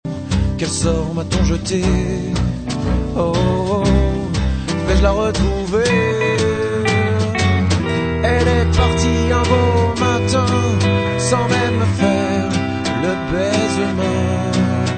chanson influences jazz